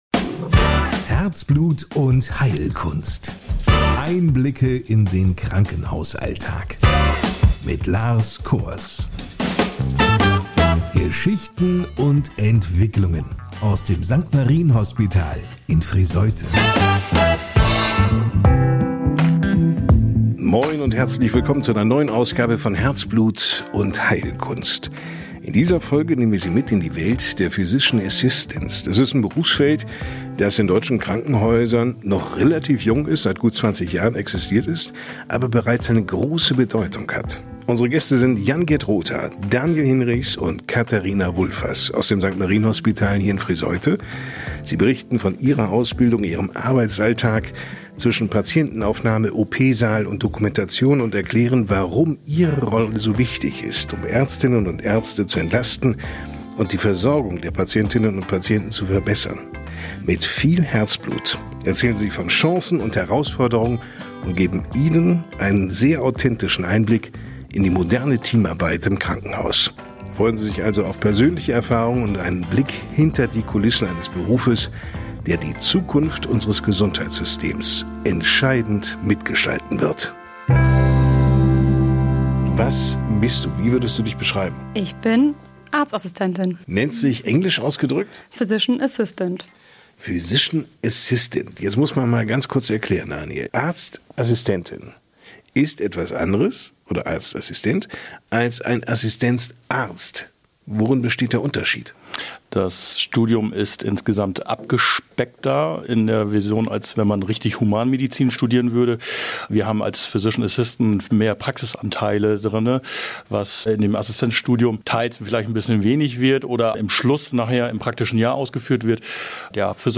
In dieser Folge sprechen drei Physician Assistants aus dem St.-Marien-Hospital Friesoythe über ihren abwechslungsreichen Berufsalltag, den Weg in den PA-Beruf und die zentrale Rolle, die sie im Krankenhaus einnehmen.